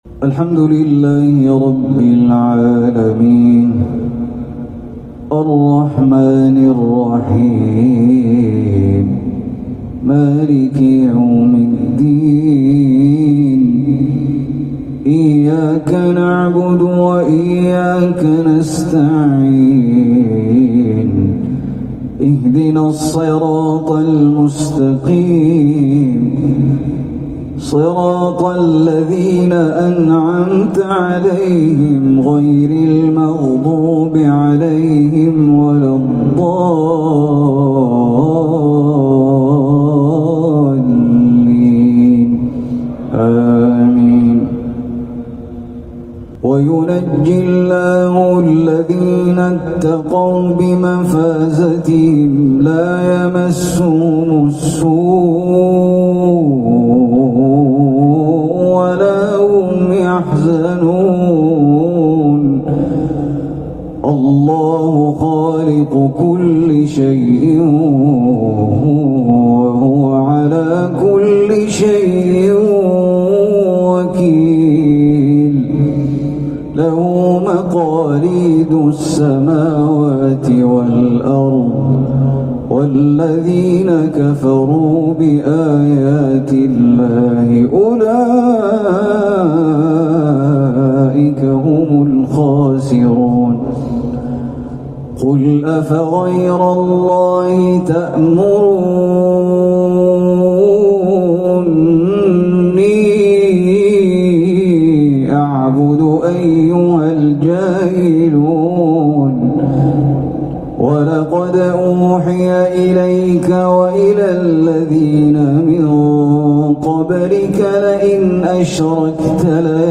بمسجد سعود الزغيبي، بحي العاقول بالمدينة النّبوية